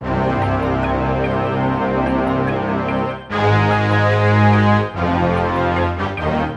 Tag: 146 bpm Trap Loops Brass Loops 1.11 MB wav Key : C